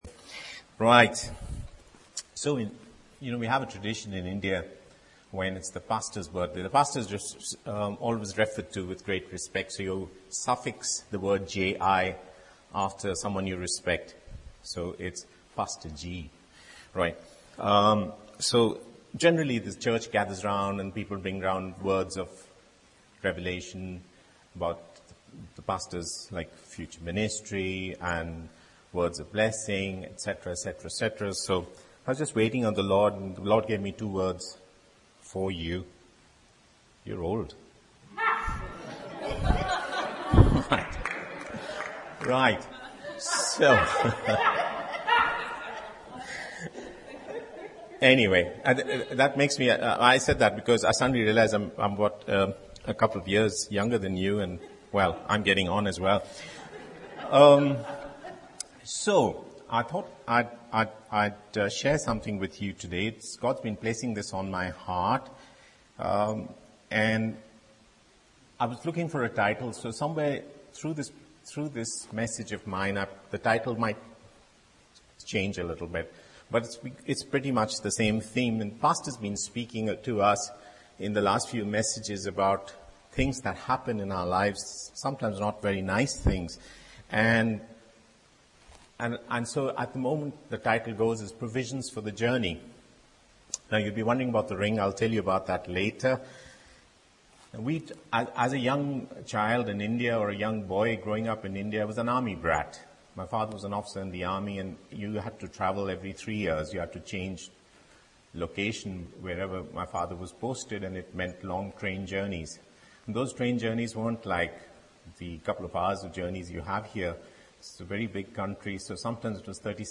This is an excellent message which helps us to see things from God's perspective.